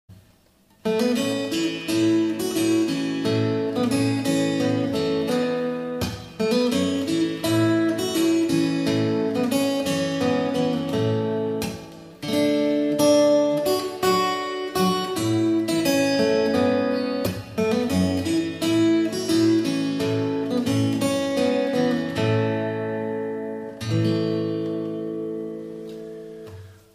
Standard Tuning - 4/4 Time
oh_suzanna_fingerstyle.mp3